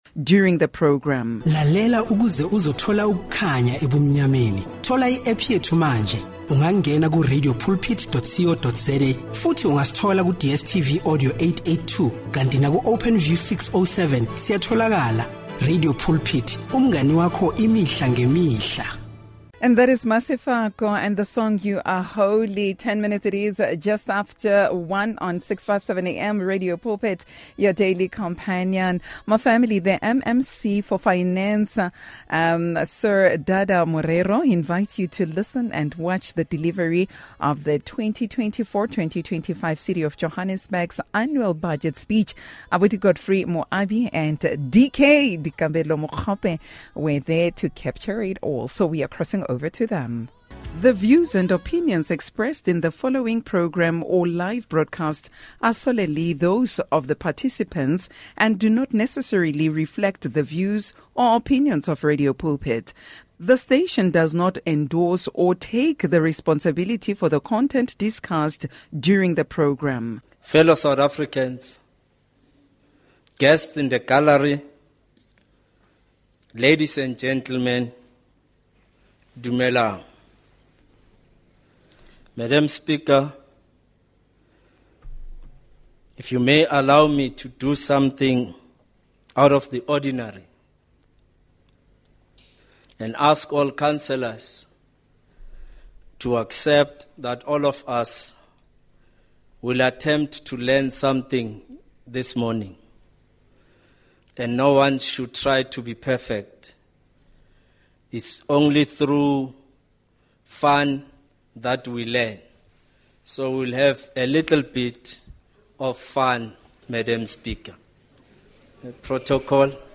The MMC for finance in the City of Johannesburg delivers 2024/25 city of Johannesburg's annual budget speech.